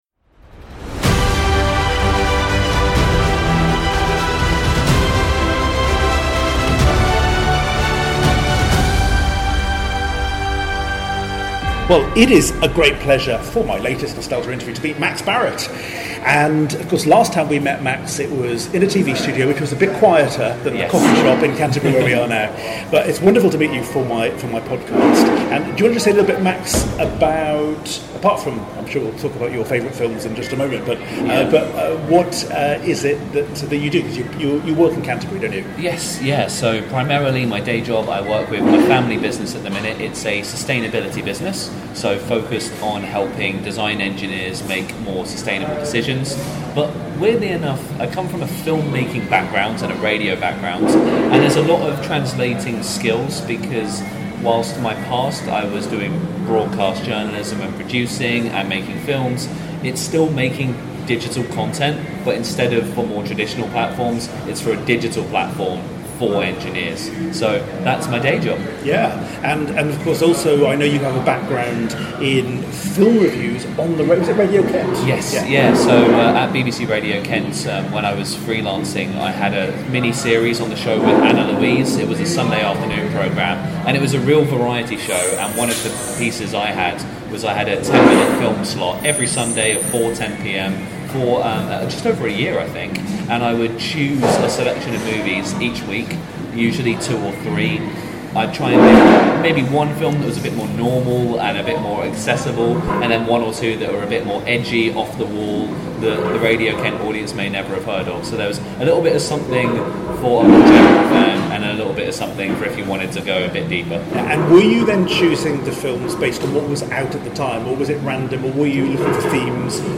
Nostalgia Interviews